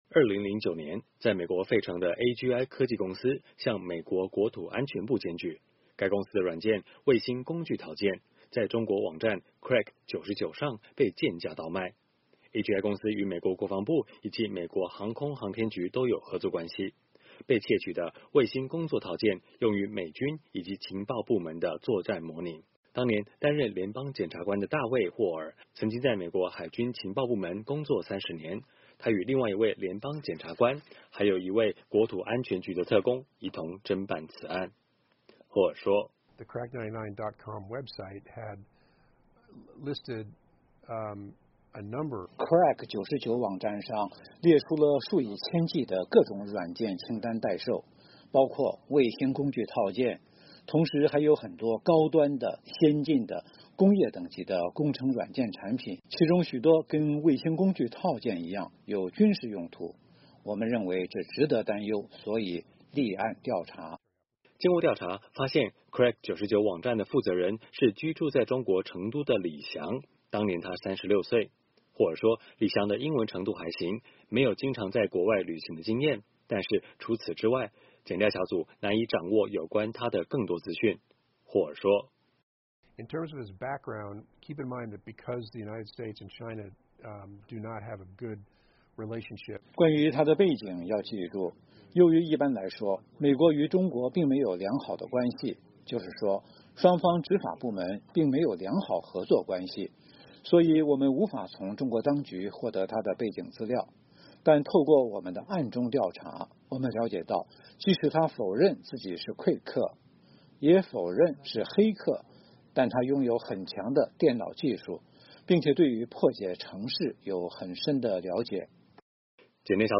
专访：人赃俱获，智破窃美军机中国网（上）